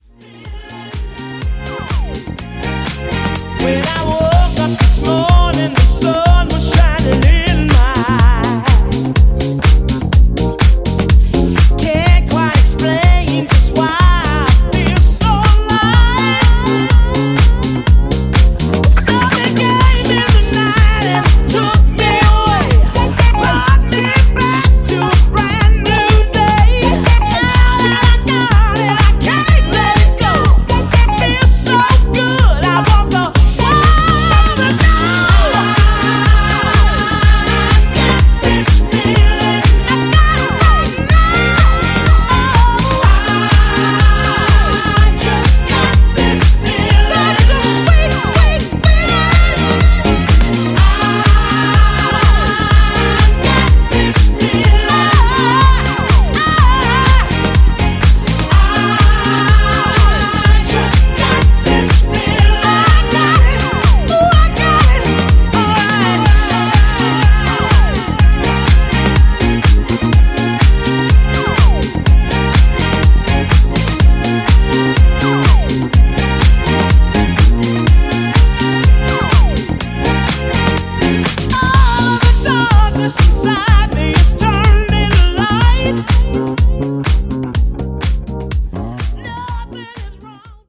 swirling disco-funk treat